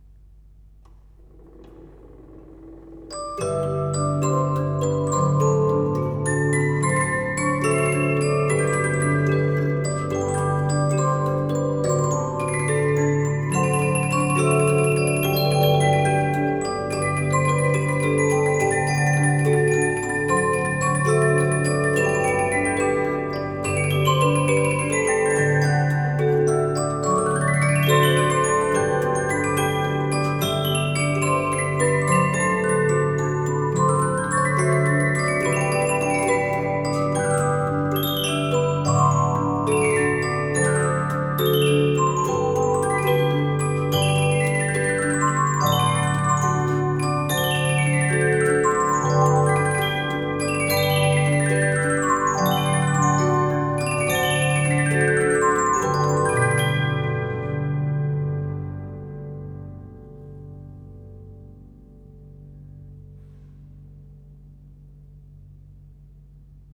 Nahrávání z polyfonního přístoje
Přístroje byly ve vynikajícím stavu a do výsledných záznamů jsem nikterak nezasahoval.
Úžasný,zírám na slušný basy a krásnej dozvuk.